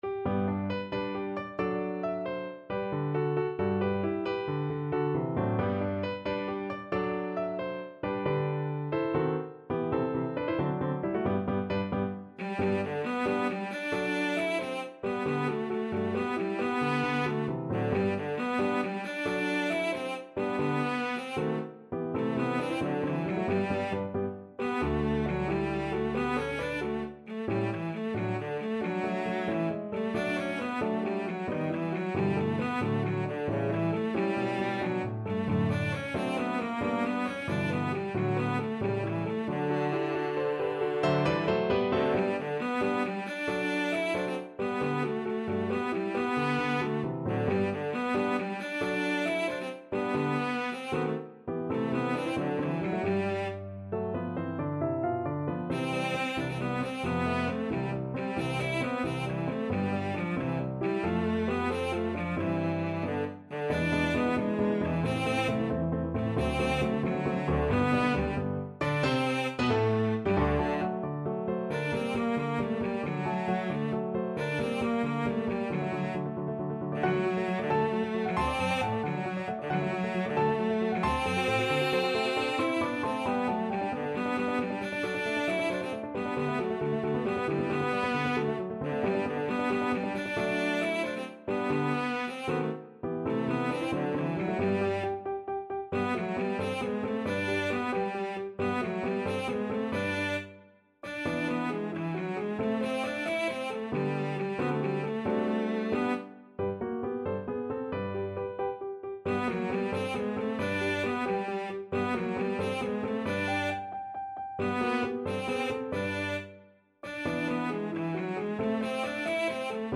. = 90 Allegretto vivace
6/8 (View more 6/8 Music)
Cello  (View more Intermediate Cello Music)
Classical (View more Classical Cello Music)